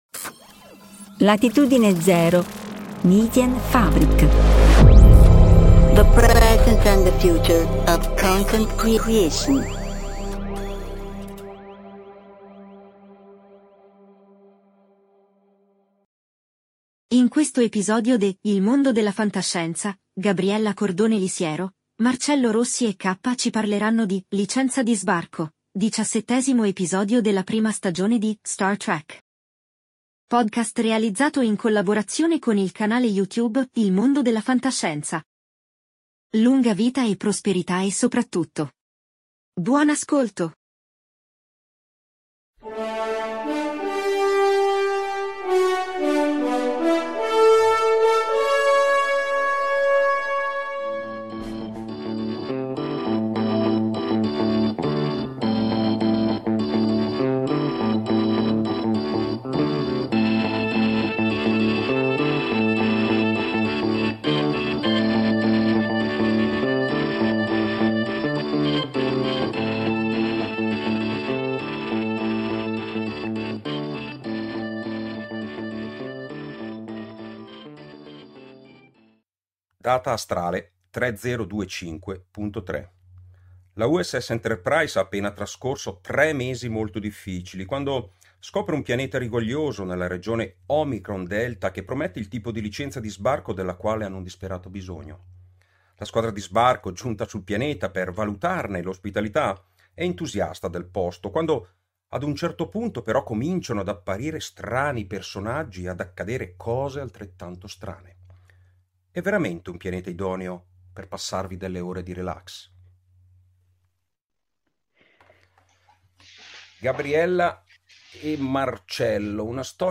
Incontro con Bob O'Reilly - Deepcon 23 – FantascientifiCast – Podcast